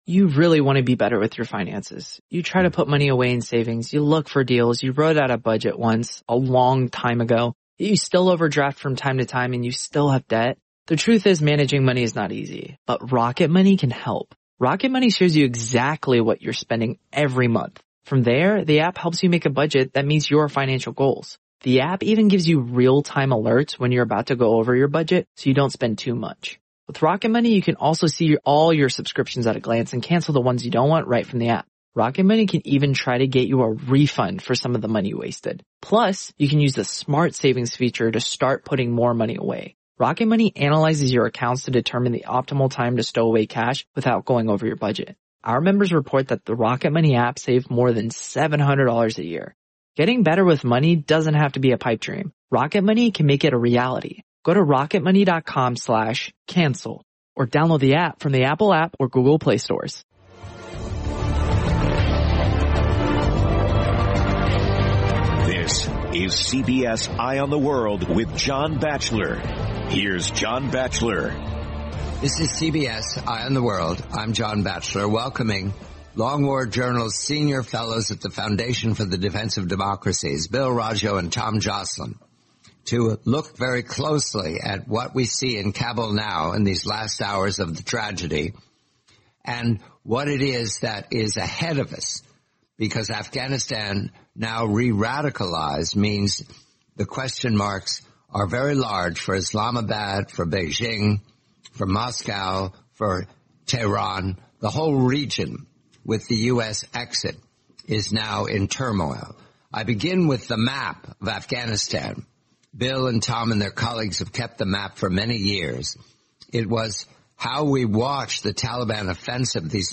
the complete, forty-minute interview